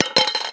硬币掉入铁罐 " 硬币掉落2
描述：单枚硬币掉进一个罐子里